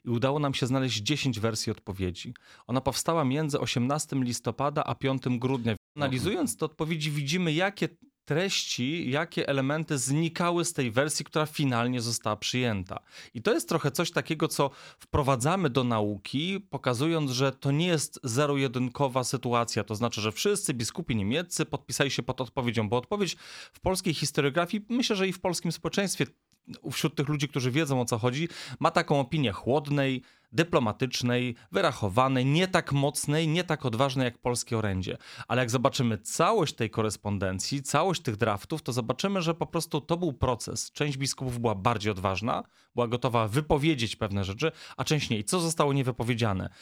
Jak tłumaczy historyk, wynikało to z różnic w sytuacji społecznej i religijnej obu krajów.